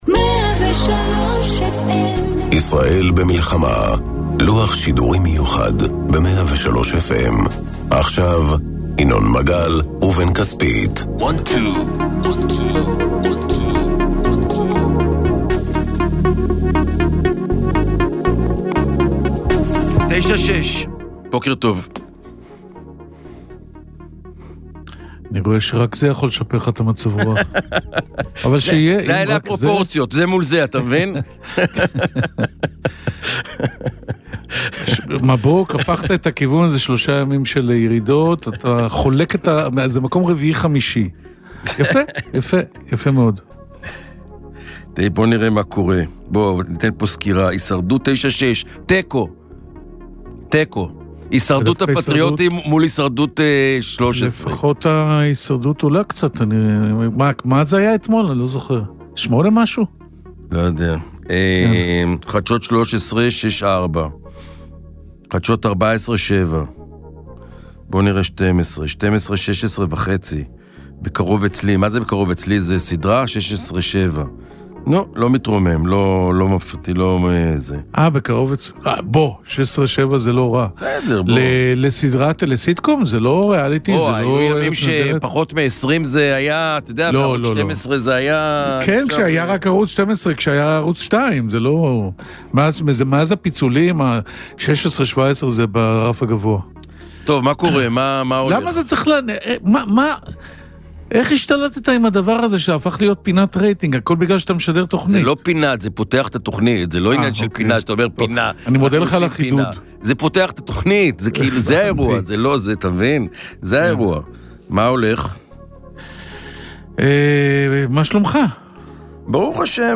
בכל יום, פותחים ינון מגל ובן כספית את המיקרופון בשעה 09:00 ועד לשעה 11:00 לא מפסיקים לדון ולהתווכח על כל הנושאים הבוערים שעל סדר היום. בין אם זה כלכלה, ביטחון, פוליטיקה, חברה ואפילו ספורט - אין נושא שלשניים אין מה להגיד לגביו. כאן תמצאו את המרואיינים המעניינים והמסקרנים ביותר שהם חלק משיחת היום - אל תדאגו, יש מקום לכולם ולמגוון דעות.